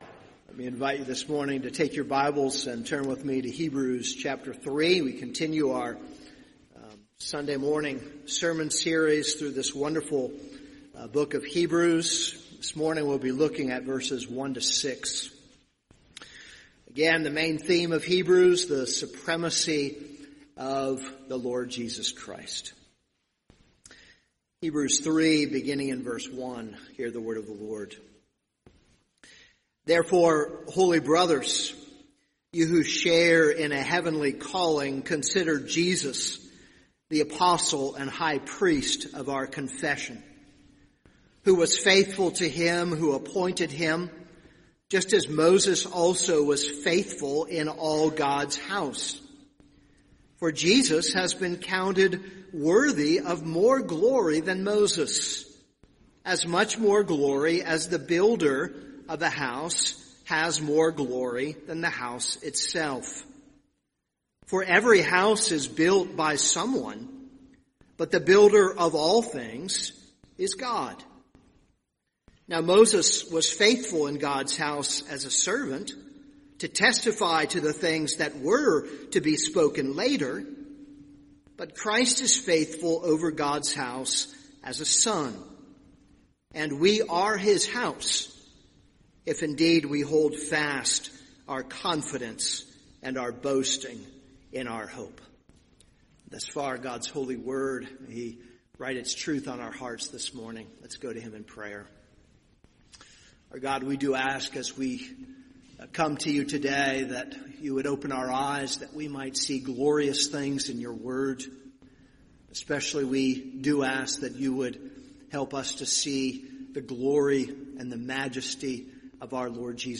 This is a sermon on Hebrews 3:1-6.